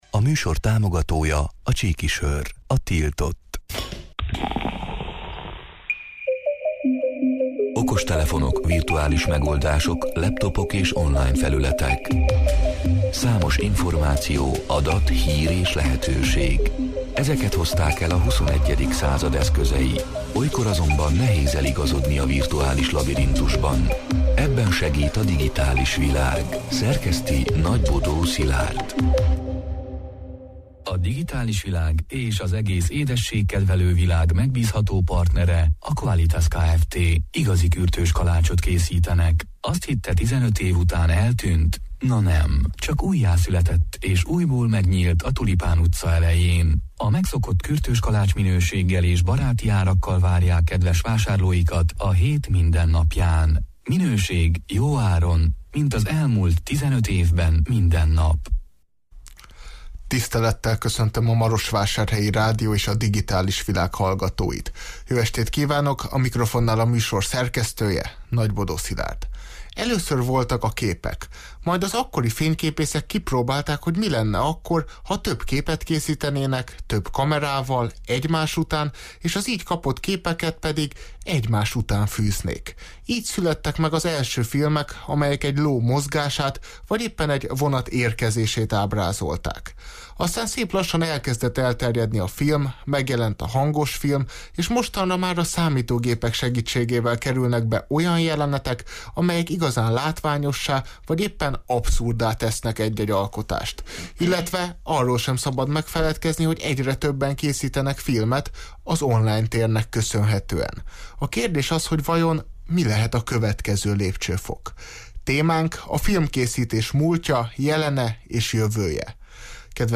A Marosvásárhelyi Rádió Digitális Világ (elhangzott: 2022. október 18-án, kedden este nyolc órától élőben) c. műsorának hanganyaga: Először voltak a képek, majd az akkori fényképeszek kipróbálták, hogy mi lenne akkor, ha több képet készítenének, több kamerával egymás után, az így kapott képeket